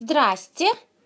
• Категория: Привет(приветствие)
• Качество: Высокое